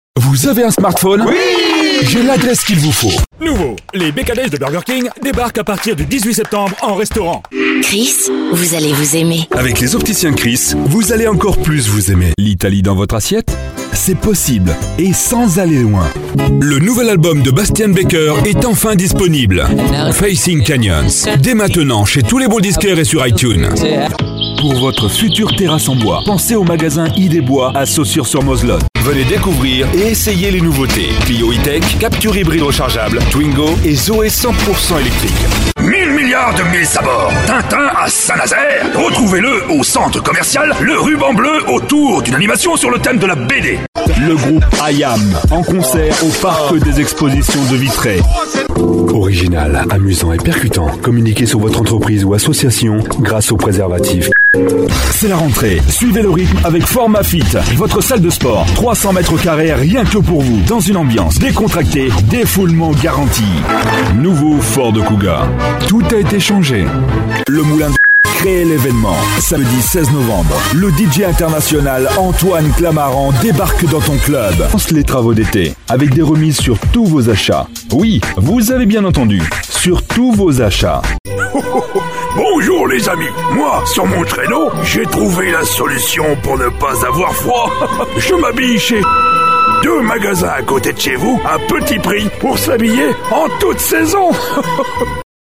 Profonde, Naturelle, Chaude, Douce, Commerciale, Polyvalente
Commercial